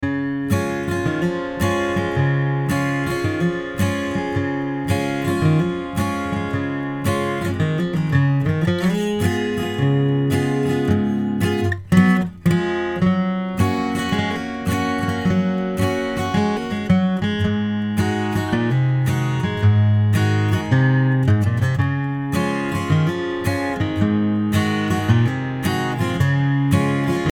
Hi, I'm working on recording my acoustic. I've only got a Studio project B1 right now and running that through a FireStudio Mobile with some Yamaha hs50m monitors. Two tracks panned left and right.
I added a little compression and the Slate FG-X in the master channel for a little loudness. Don't mine the crappy playing I just want to know if it sounds ok or not. It's a little boomy on my crappy computer speakers so I'll work on that View attachment 1.mp3